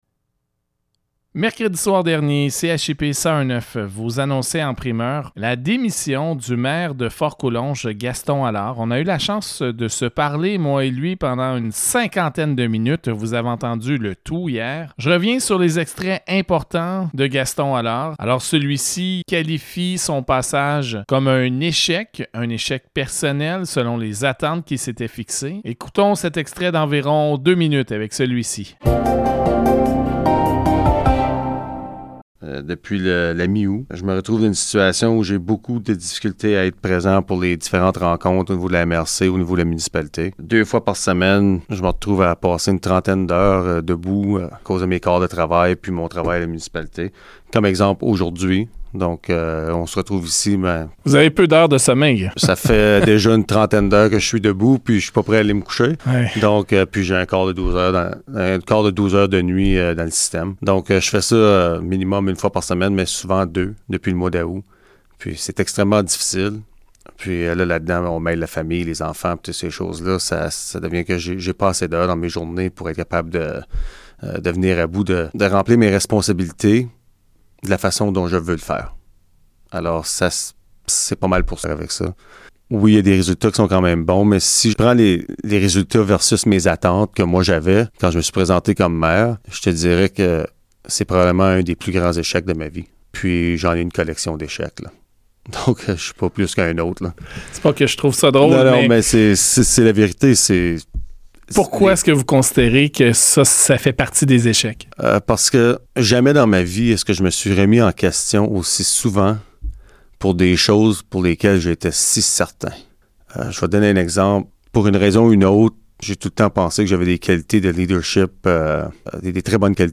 In an exclusive interview with CHIP 101.9, Allard reveals that he has a new job on a professional level which no longer allows him to exercise his functions as mayor within the council.